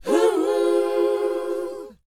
WHOA D#D U.wav